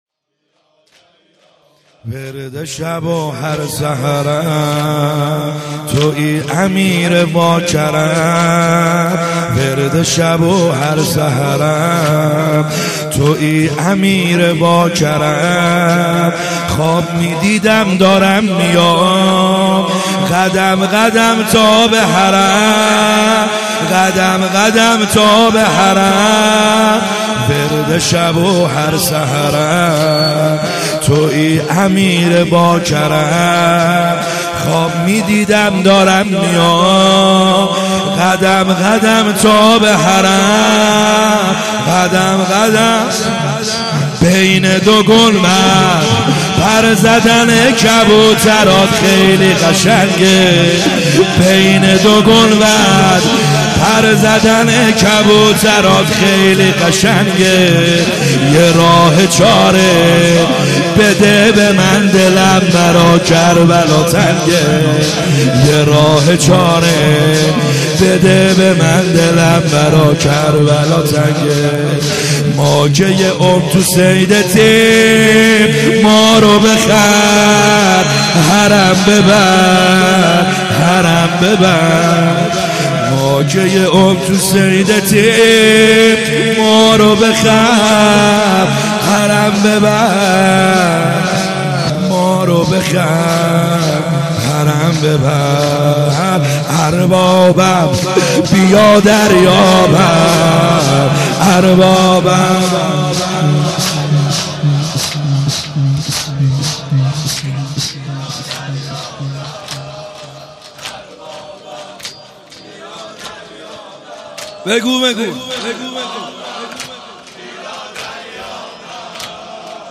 خیمه گاه - بیرق معظم محبین حضرت صاحب الزمان(عج) - زمینه | ورد شب و هر سحرم